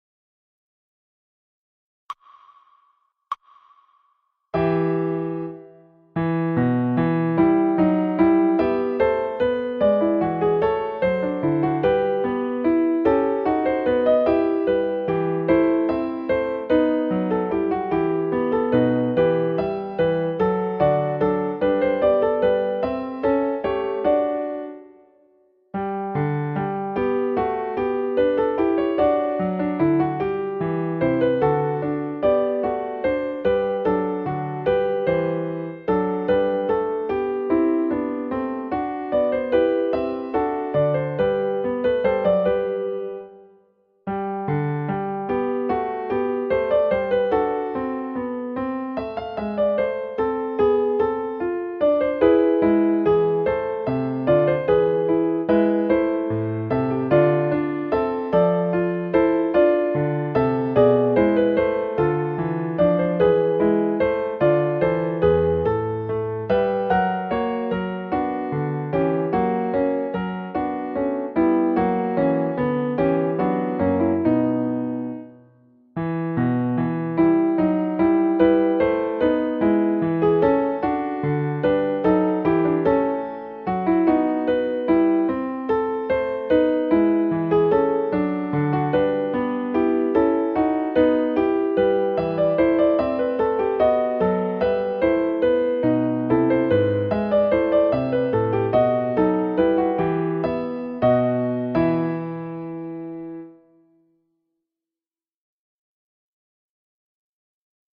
An episode by Simon Balle Music